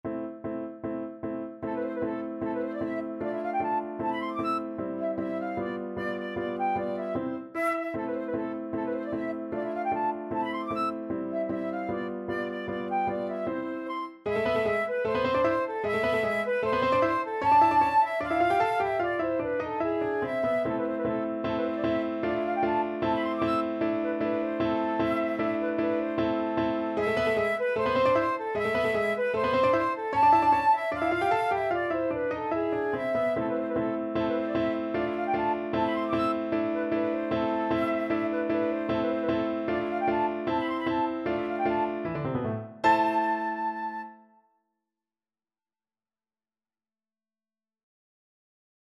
Flute version
Allegro scherzando (=152) (View more music marked Allegro)
2/4 (View more 2/4 Music)
Classical (View more Classical Flute Music)